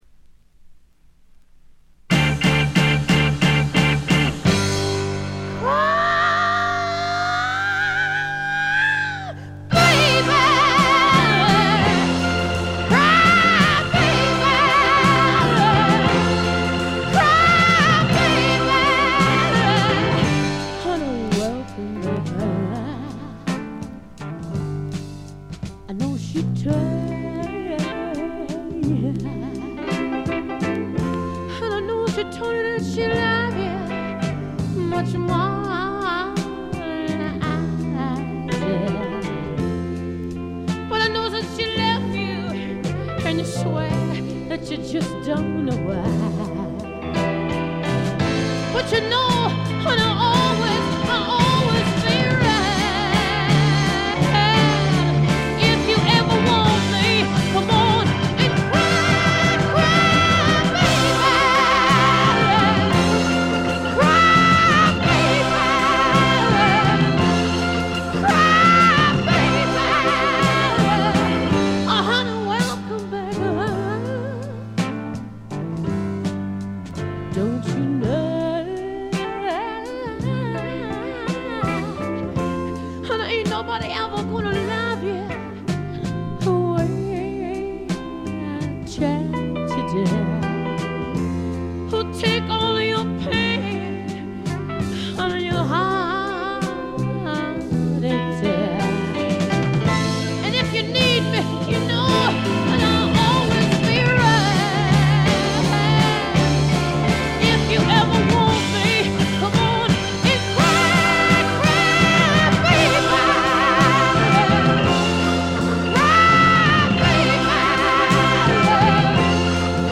Vocals、Acoustic Guitar
Piano
Organ
Drums